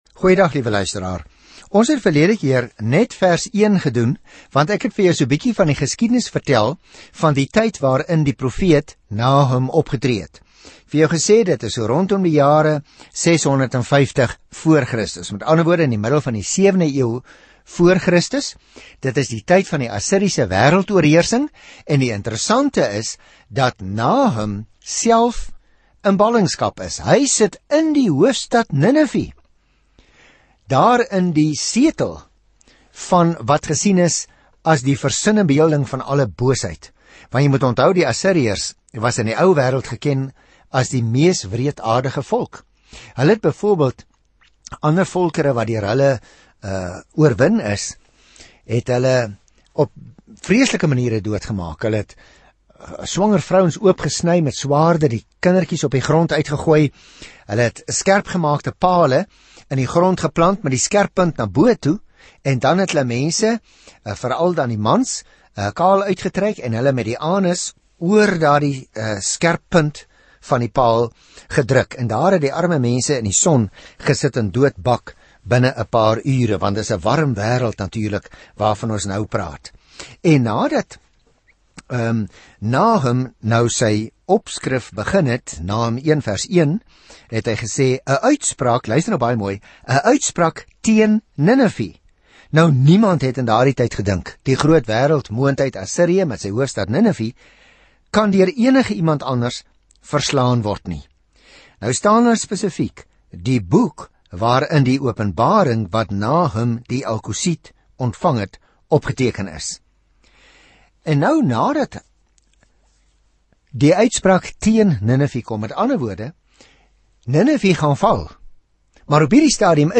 Skrif NAHUM 1:2-11 Dag 1 Begin met hierdie leesplan Dag 3 Aangaande hierdie leesplan Nahum, wie se naam troos beteken, bring 'n boodskap van oordeel aan God se vyande en bring beide geregtigheid en hoop vir Israel. Reis daagliks deur Nahum terwyl jy na die oudiostudie luister en uitgesoekte verse uit God se woord lees.